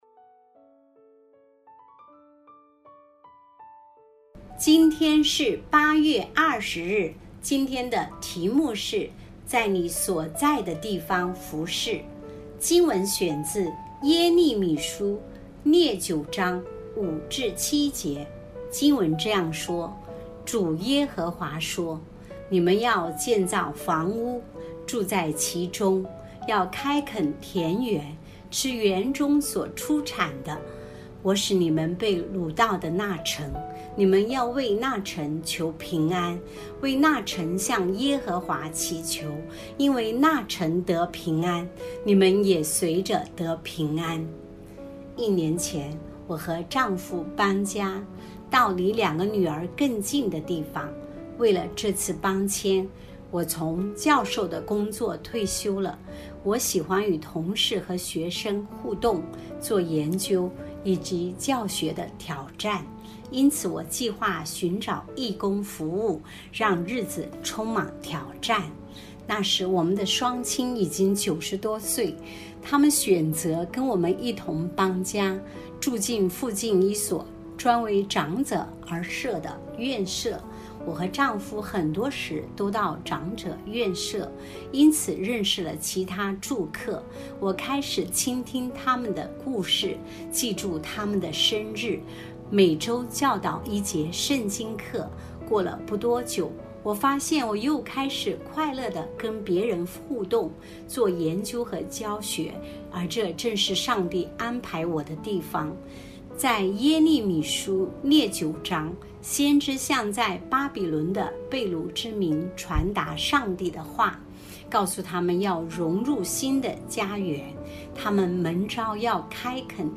錄音員